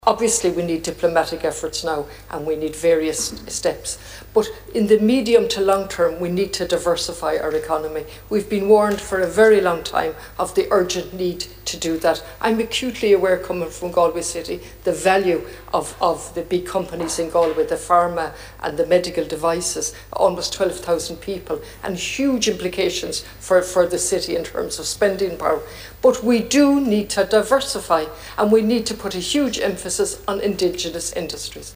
That’s according to Galway West TD Catherine Connolly, who’s spoken on the matter in the Dáil.